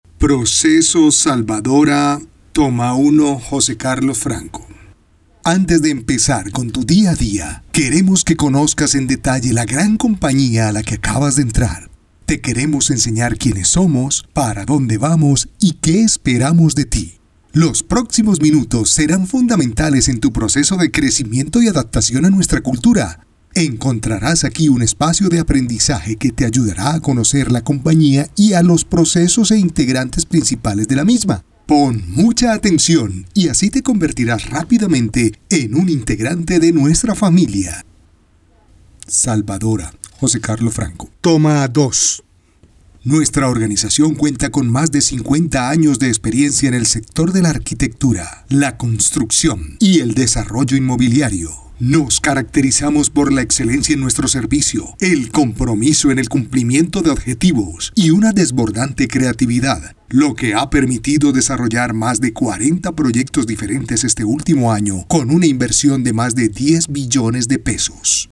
Micrófono Scarlett CM 25 Interfaz Focusrite Scarlett solo 4 Audífonos Scarlet SM 450 Adobe Audition
kolumbianisch
Sprechprobe: eLearning (Muttersprache):
My voice range is from 25 to 60 years old. My voice is warm, mature, energetic, happy, brassy, narrator tips.